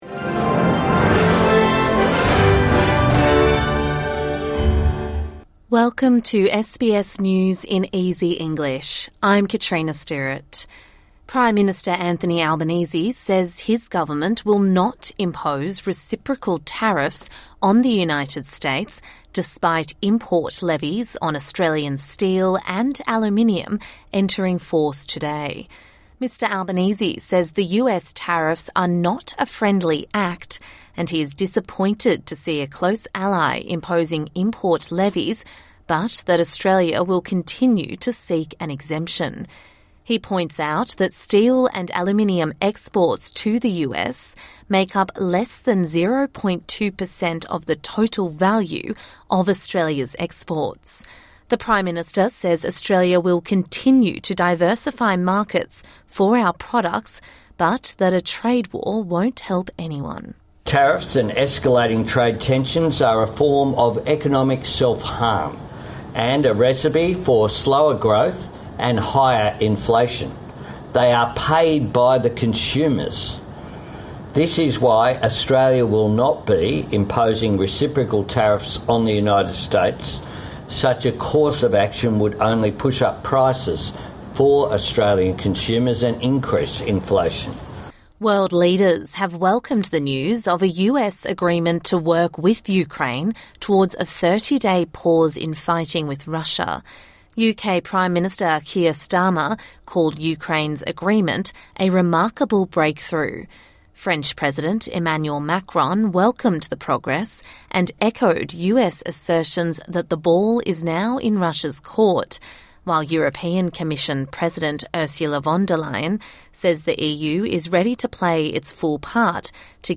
A daily 5 minute news bulletin for English learners and people with a disability.